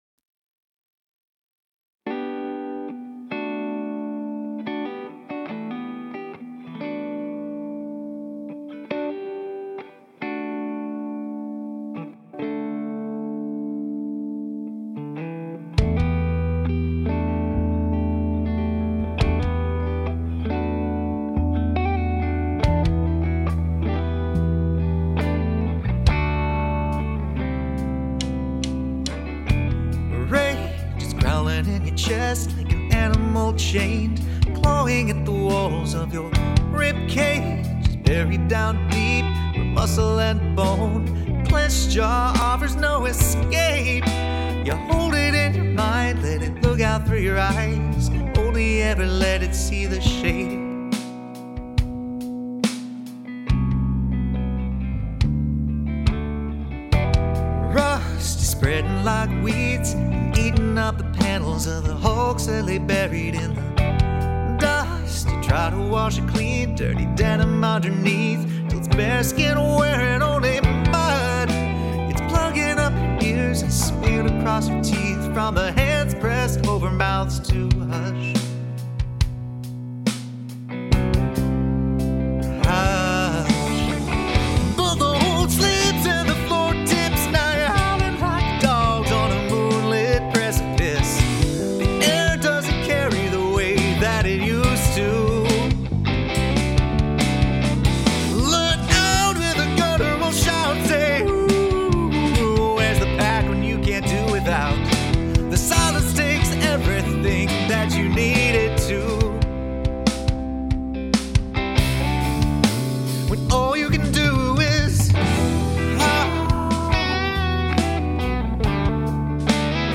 by the way, 4.43 on that guitar, is that an intentional move?
I'll give you an example of what I mean, towards the end of the song the word howl is sung 3 times, you nail the first one and sing it perfectly, the second is not good and the third is expressive but middling. If I were mixing this, and it's all I had, I would use the first 'howl' three times to get what I think is a better result.